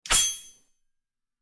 SWORD_05.wav